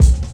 Kick_25_b.wav